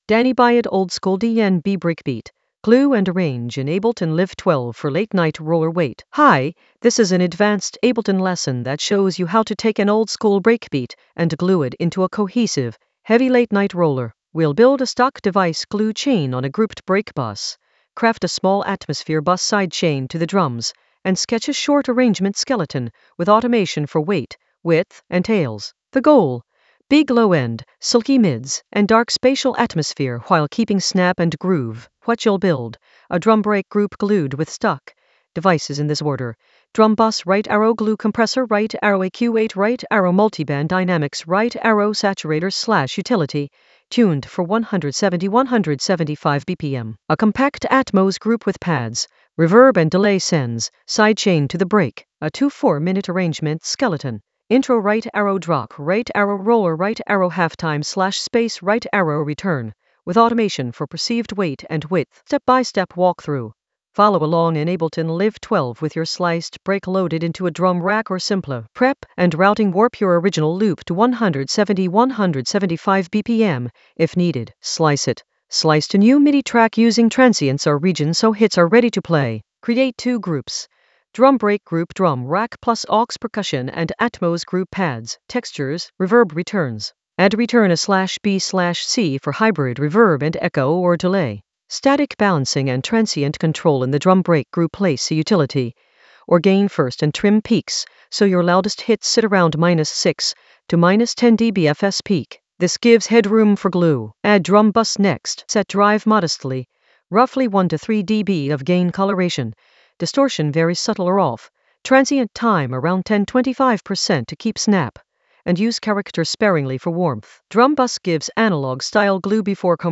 An AI-generated advanced Ableton lesson focused on Danny Byrd oldskool DnB breakbeat: glue and arrange in Ableton Live 12 for late-night roller weight in the Atmospheres area of drum and bass production.
Narrated lesson audio
The voice track includes the tutorial plus extra teacher commentary.